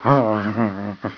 grrr.wav